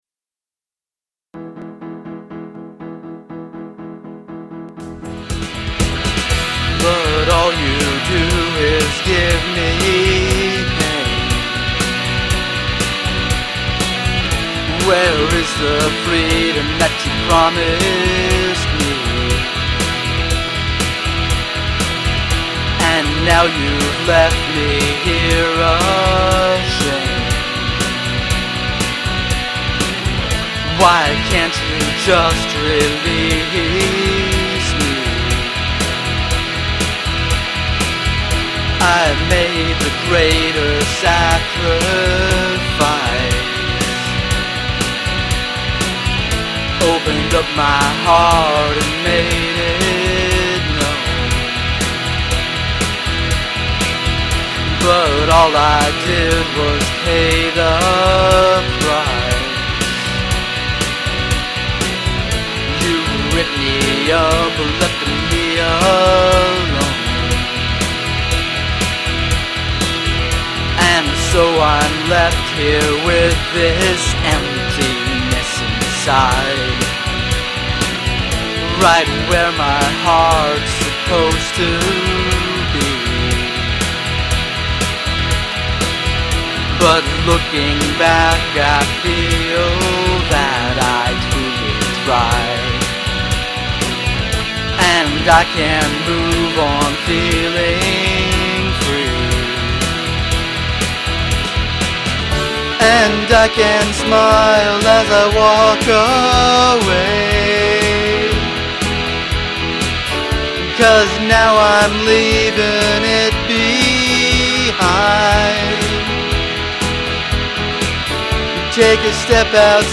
The guitar solos are decent, but I recall doing much better.